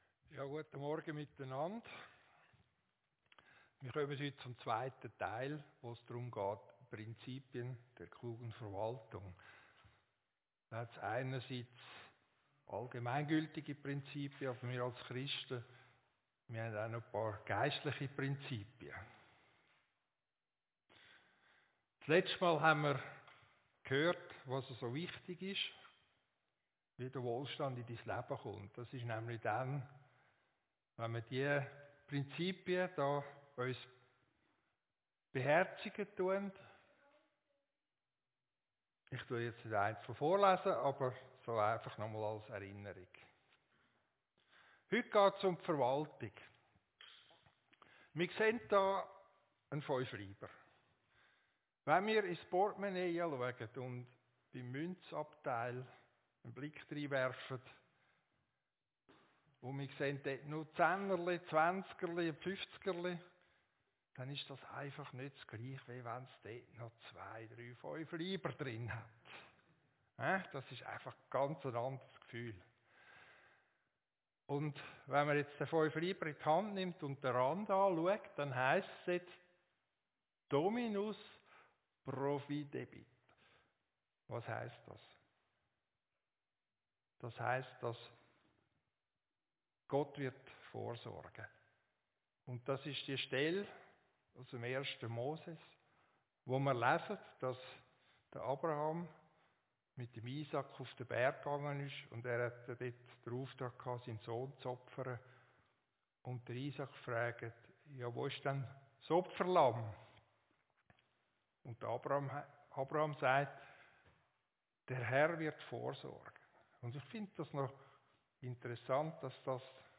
Predigt-9.6.24.mp3